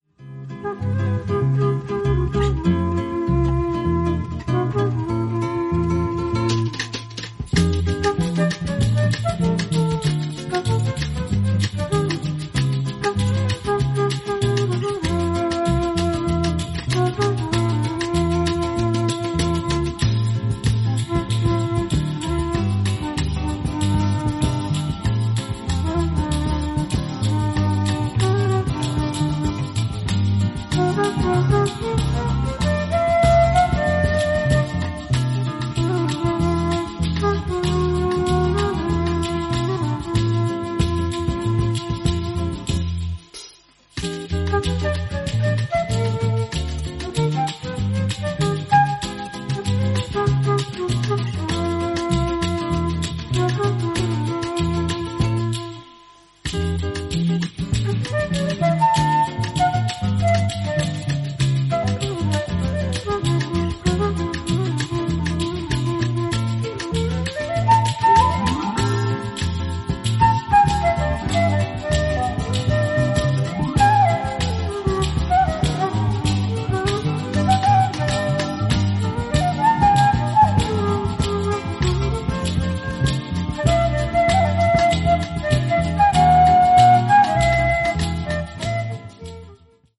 イタリアのライブラリーらしく、メロディが綺麗なボッサやソフトロックも良いですね。